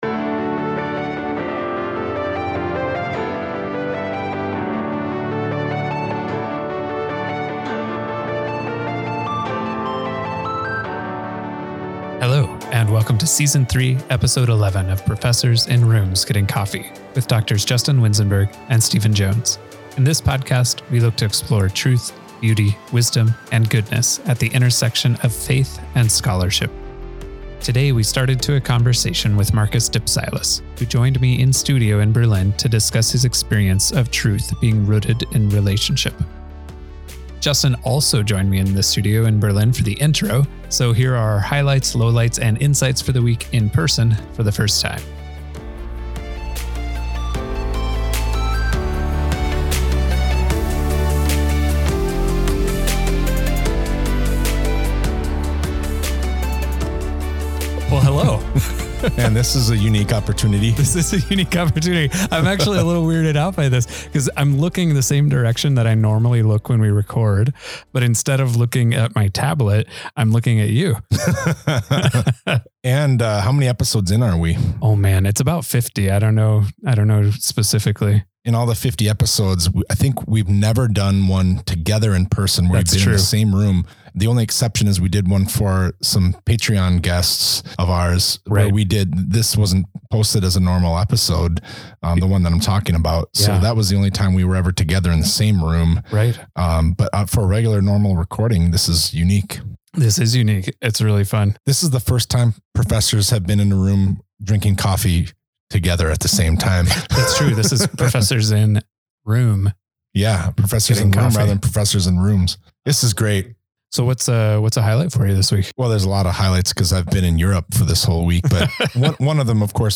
This is the first time the guys were in the same room while recording an episode!